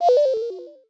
Index of /phonetones/unzipped/Samsung/SGH-i310/System Sounds
ActiveSyncFail_T.wav